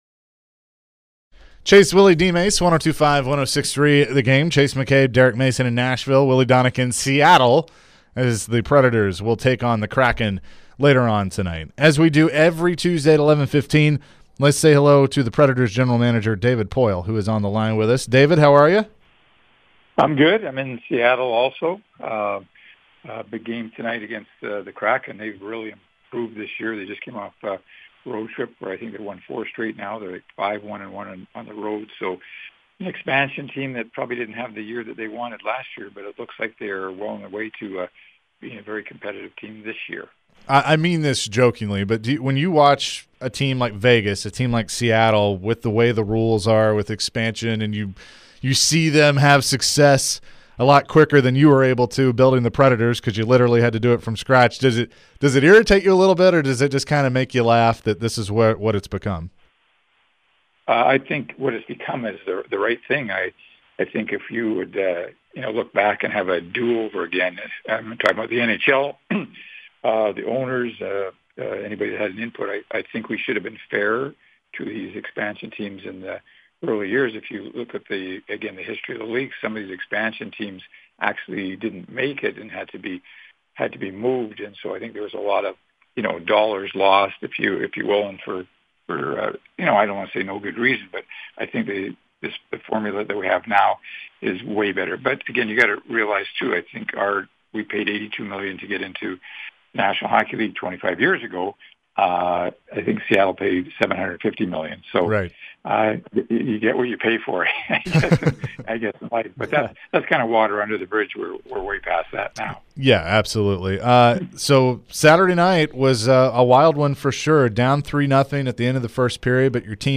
David Poile Full Interview (11-08-22)